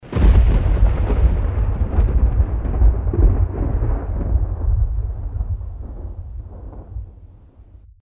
thunder3.mp3